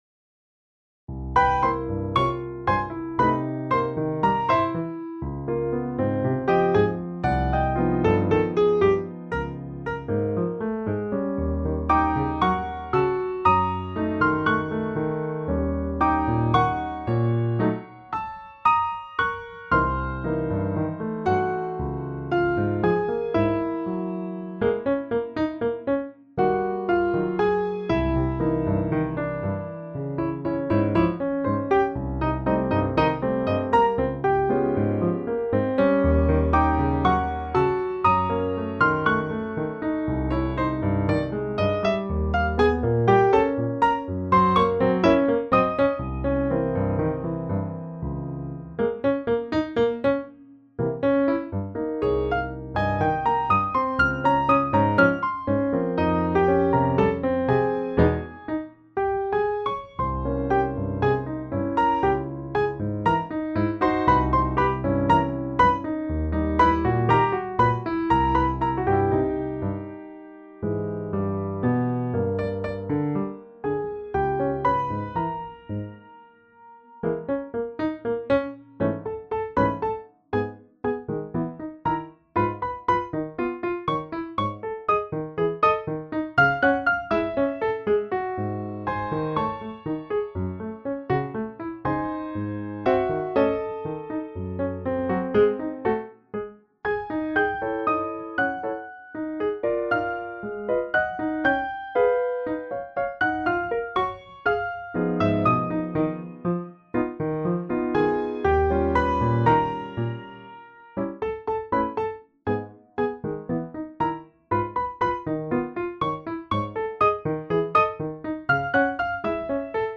Piano duet